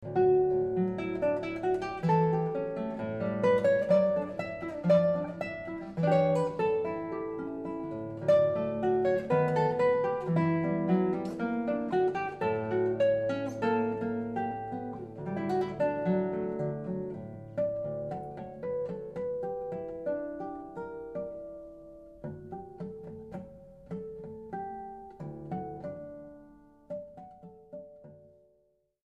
New Zealand classical guitarist composer